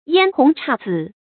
嫣紅姹紫 注音： ㄧㄢ ㄏㄨㄥˊ ㄔㄚˋ ㄗㄧˇ 讀音讀法： 意思解釋： 指花色嬌艷，亦指嬌艷的花。